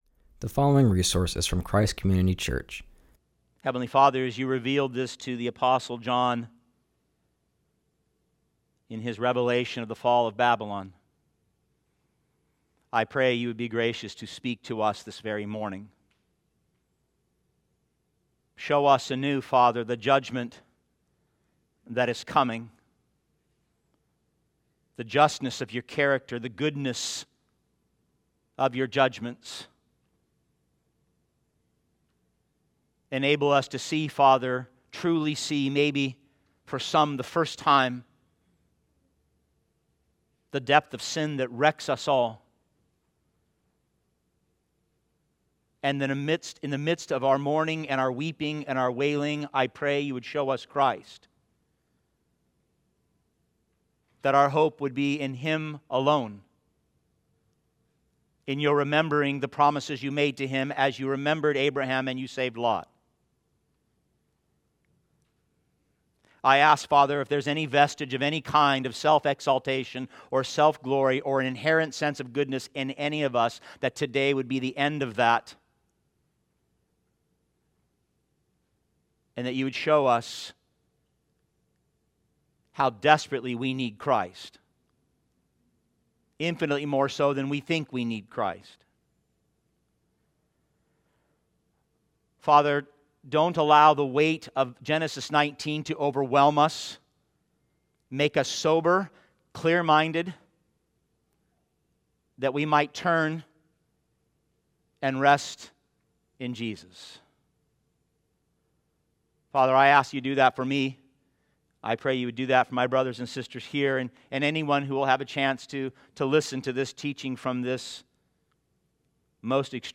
preaches from Genesis 19:1-38.